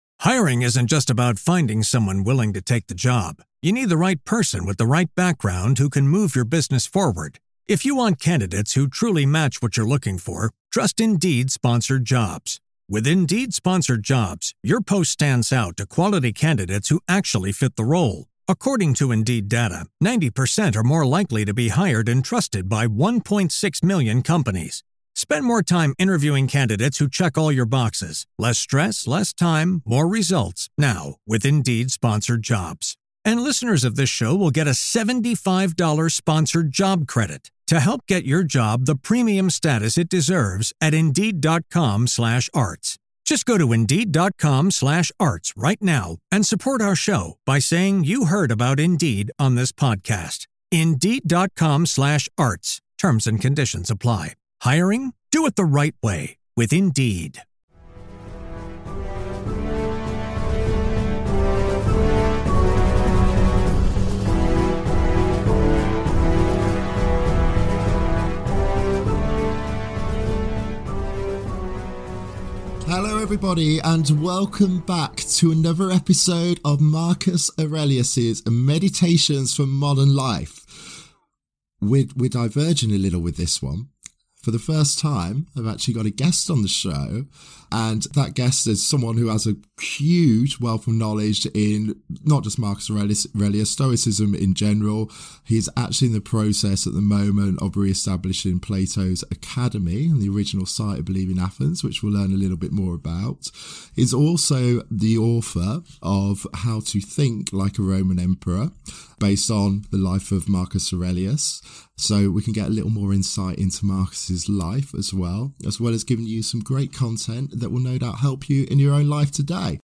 We chat about all things stoicism!